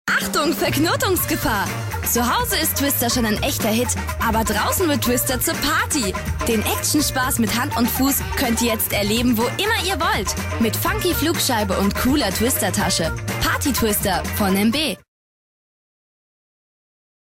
deutsche Profisprecherin. Als Sprecherin von Mädchen bis junge Frau einsetzbar
Sprechprobe: Industrie (Muttersprache):
german female voice over talent.